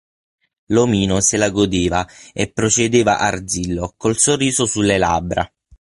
làb‧bra
/ˈlab.bra/